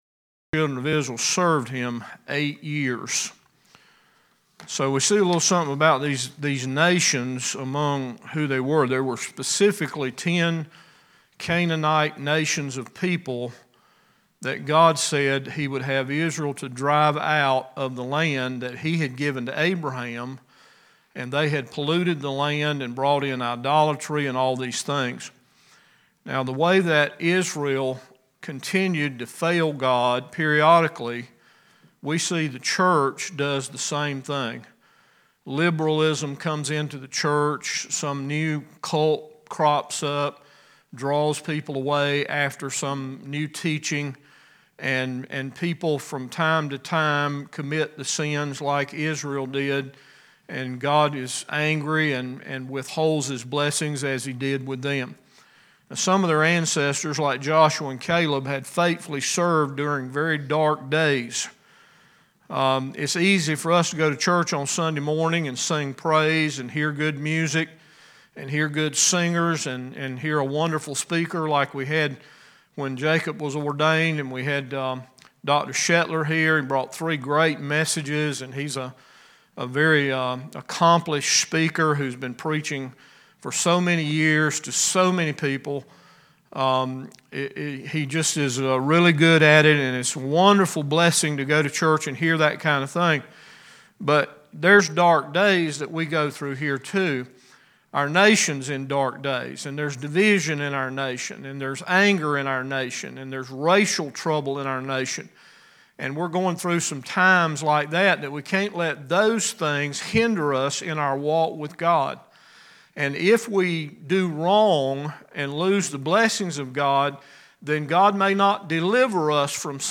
Judges Bible Study 2 – Bible Baptist Church